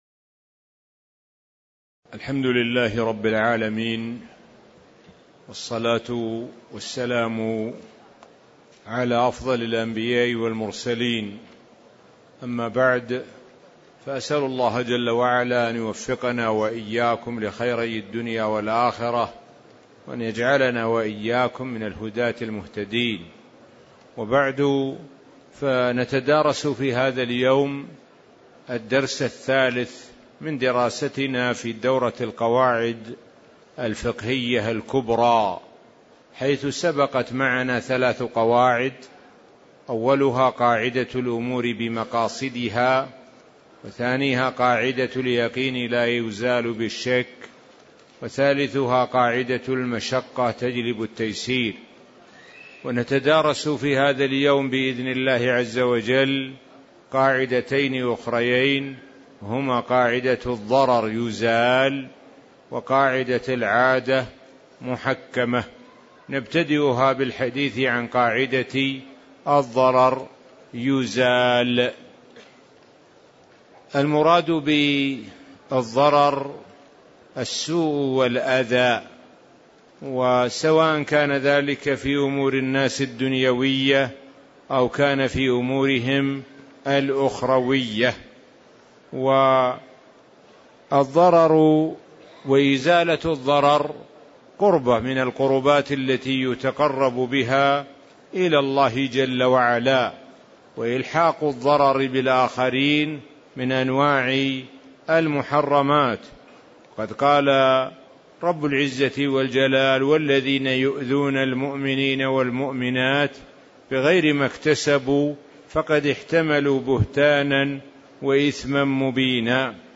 تاريخ النشر ١ جمادى الآخرة ١٤٣٧ هـ المكان: المسجد النبوي الشيخ: معالي الشيخ د. سعد بن ناصر الشثري معالي الشيخ د. سعد بن ناصر الشثري قاعدة الضرر يزال وقاعدة العادة محكمة (03)